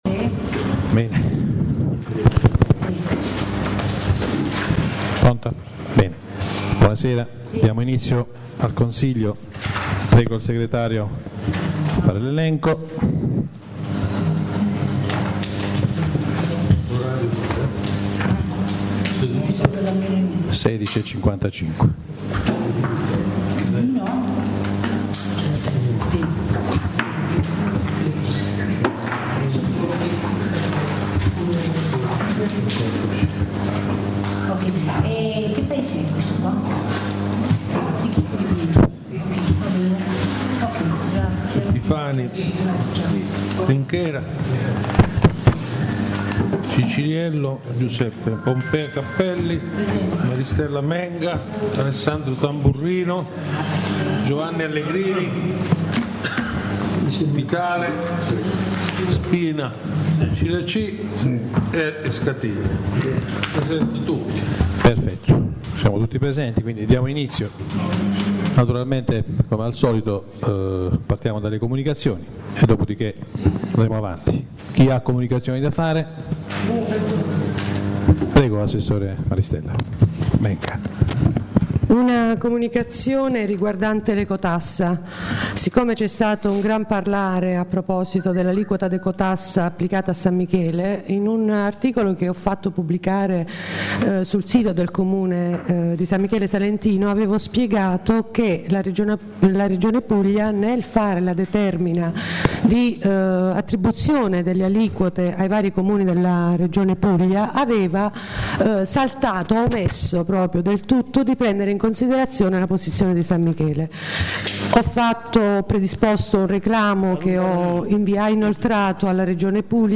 La registrazione audio del Consiglio Comunale di San Michele Salentino del 01/04/2015